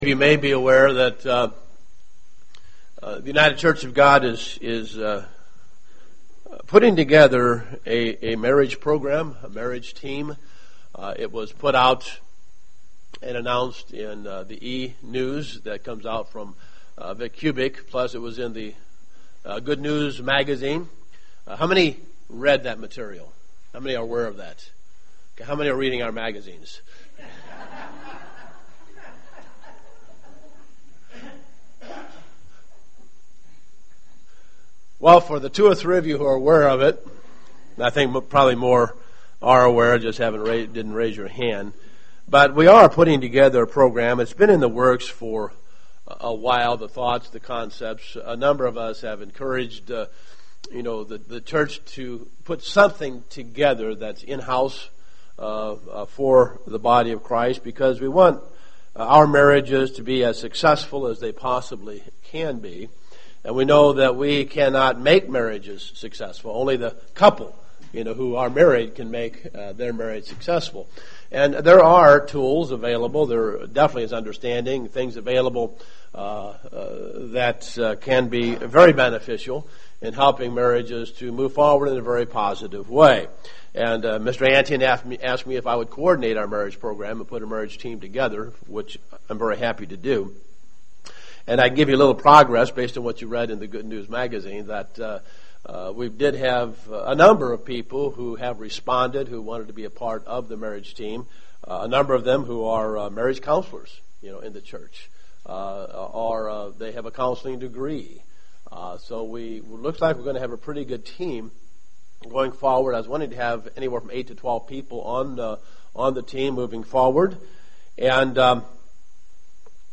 Given in Dayton, OH
UCG Sermon Studying the bible?